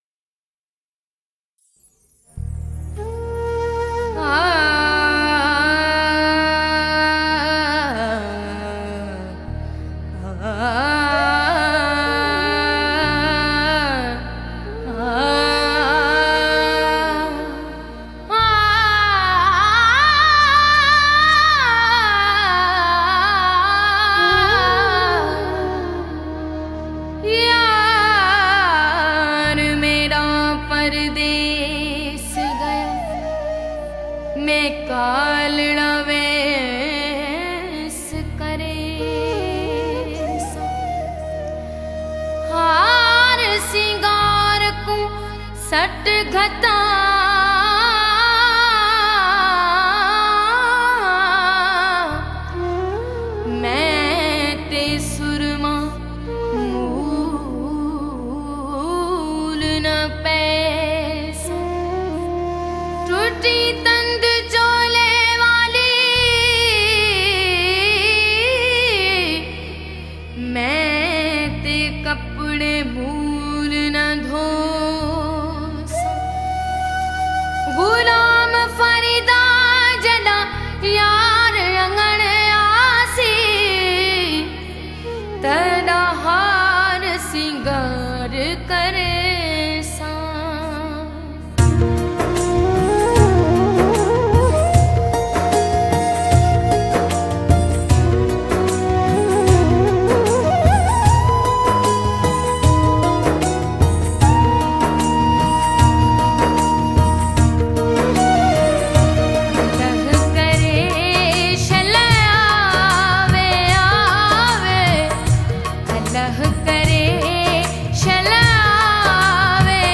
Sufi Music